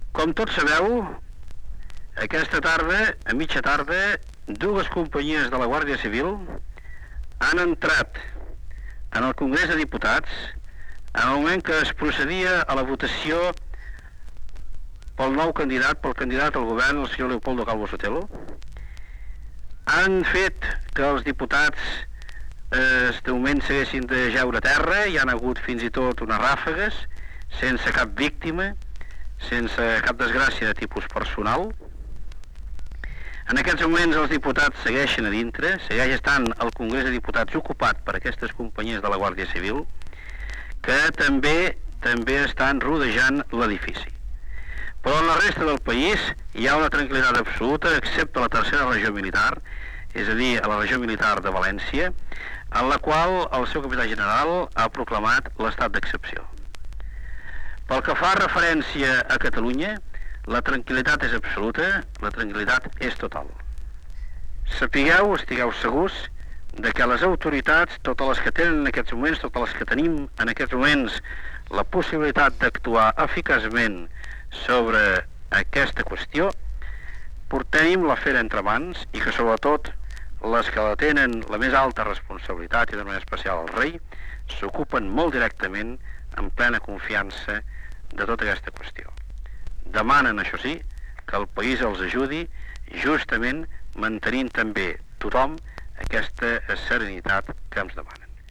Paraules del president de la Generalitat Jordi Pujol sobre l'intent de cop d'estat a Espanya: la situació a l'edifici del Congrés de Diputats de Madrid i a València.
Informatiu
FM